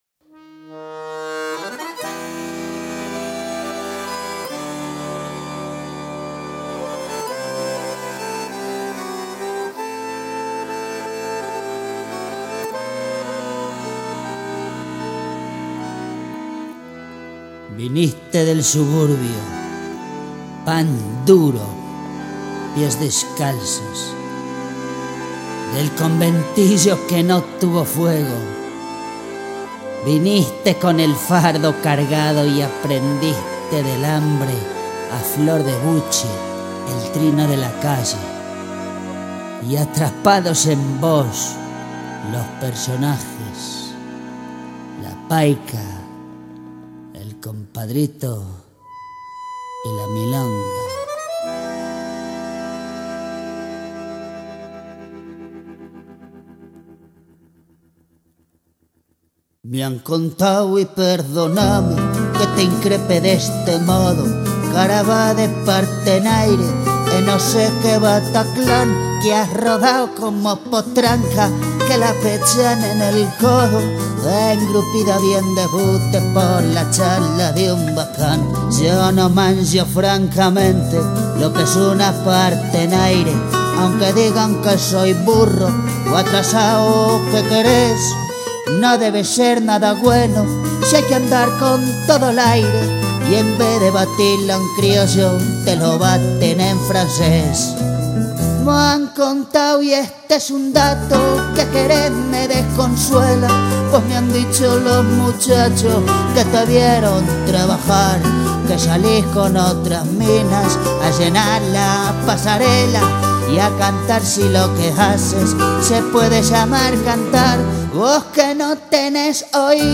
bajo
guitarra
tangos de amor